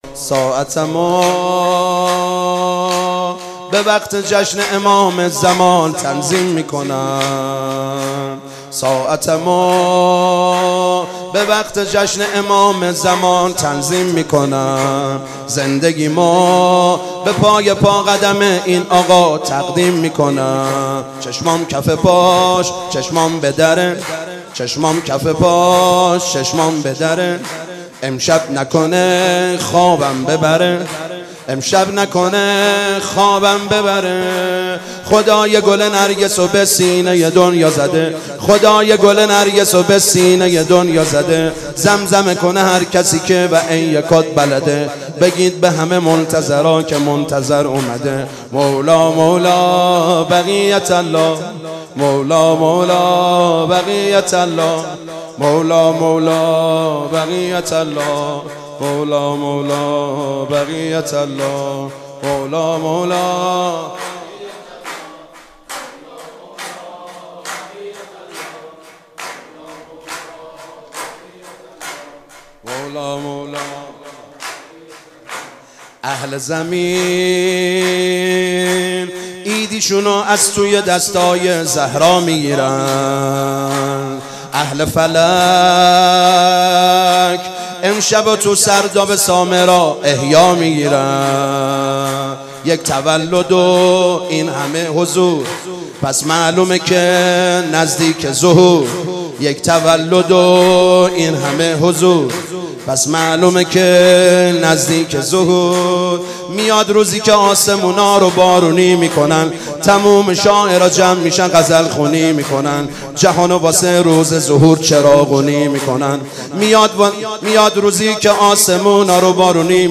میلاد حضرت مهدی (عج) 1396